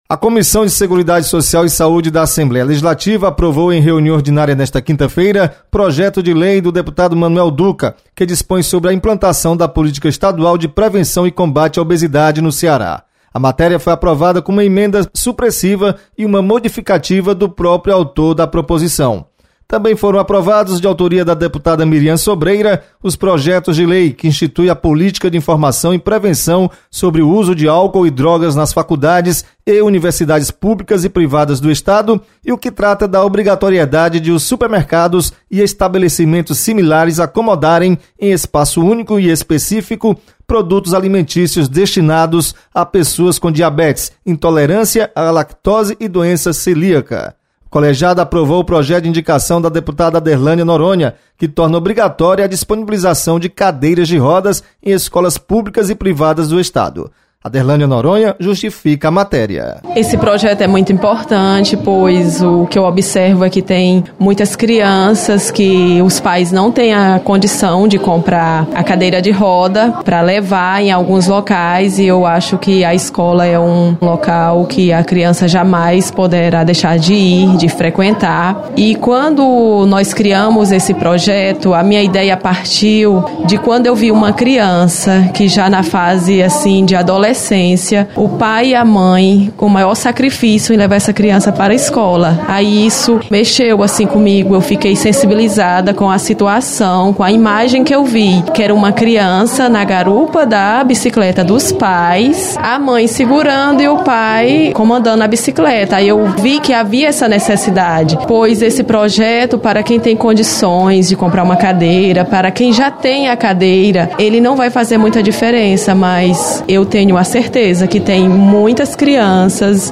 Comissão de Seguridade Social realiza reunião nesta quinta-feira. Repórter